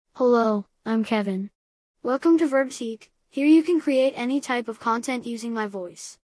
KevinMale US English AI voice
Kevin is a male AI voice for US English.
Voice sample
Listen to Kevin's male US English voice.
Male